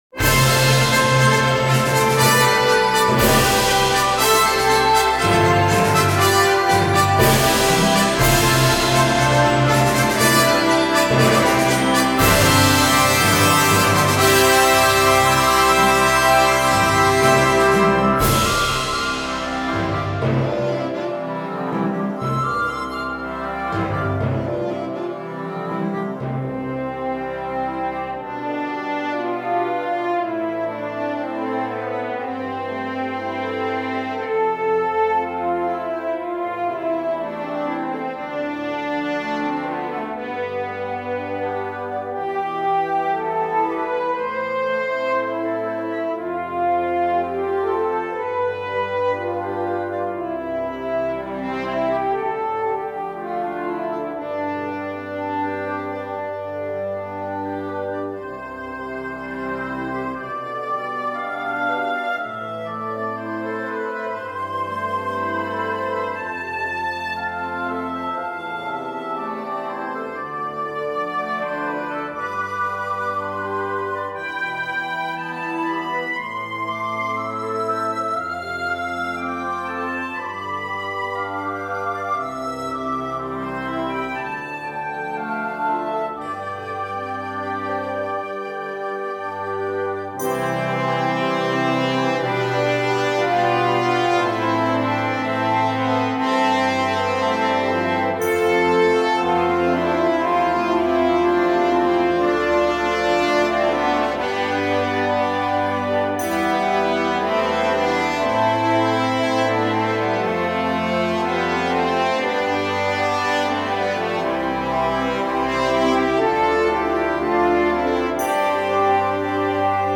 CategoryConcert Overture
Timpani
Sleigh Bells
Glockenspiel
Tubular Bells